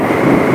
GALE.WAV